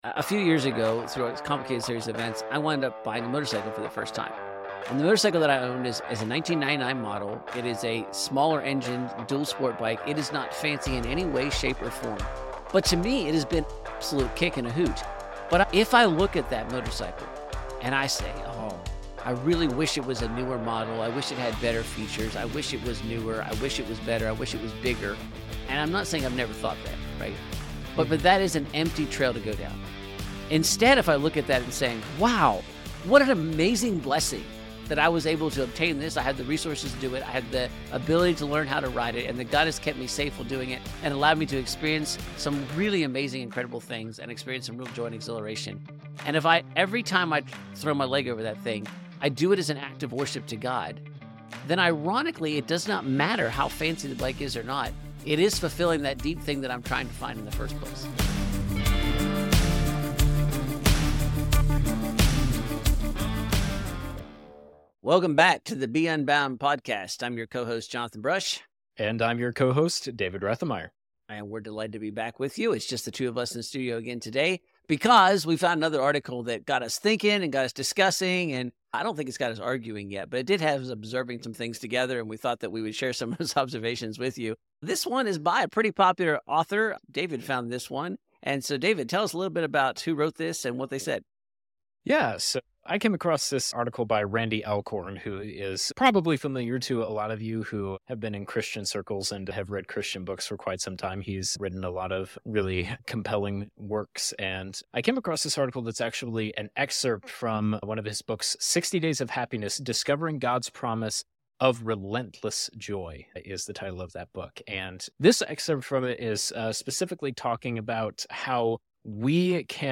We're back together for another conversation in our Unbound Talks series. Today, we dive into the profound topic of finding joy, meaning, and worship in everyday activities and blessings.